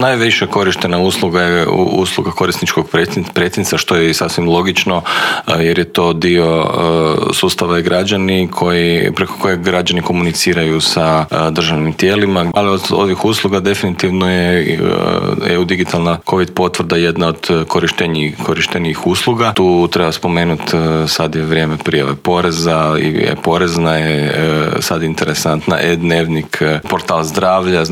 ZAGREB - U Intervjuu tjedna Media servisa gostovao je državni tajnik u Središnjem državnom uredu za razvoj digitalnog društva Bernard Gršić koji nam je otkrio kako je proteklo prvo online samopopisivanje građana, koliko ljudi koristi sustav e-Građani, što sve taj sustav nudi, što planira ponuditi kao i koje su njegove prednosti.